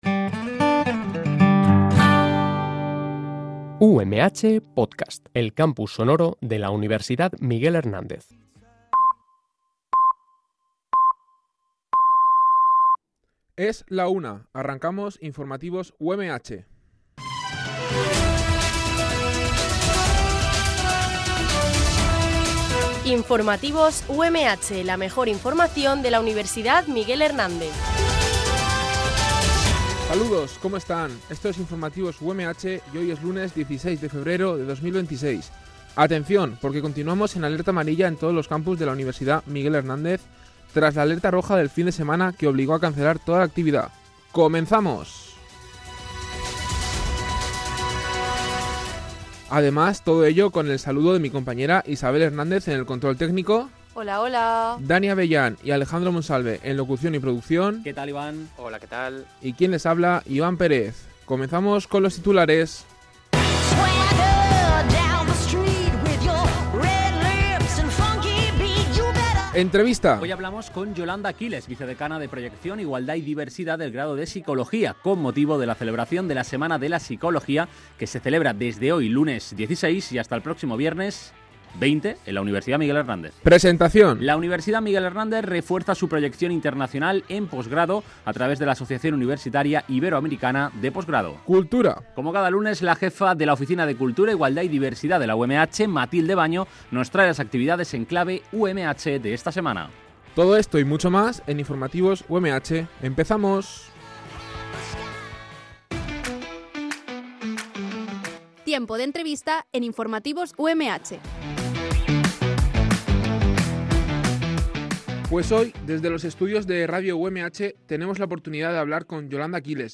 Este programa de noticias se emite en directo, de lunes a viernes, en horario de 13.00 a 13.10 h.